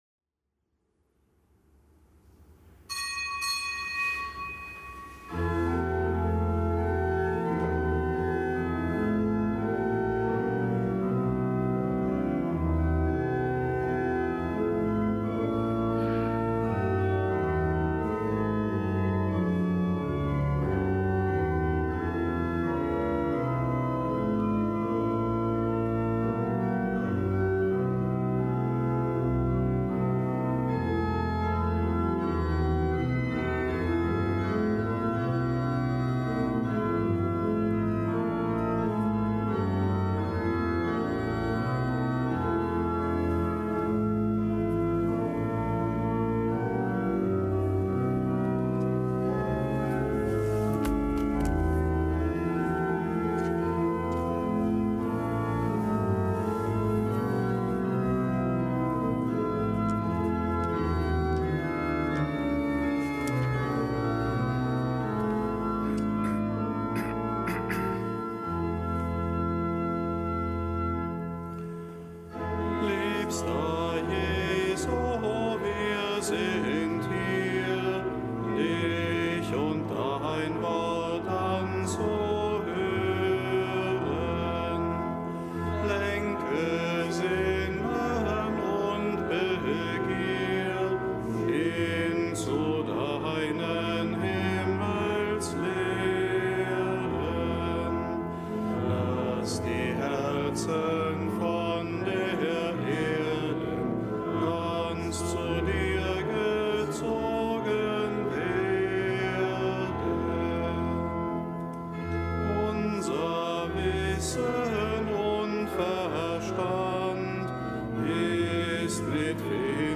Kapitelsmesse aus dem Kölner Dom am Dienstag der ersten Woche im Jahreskreis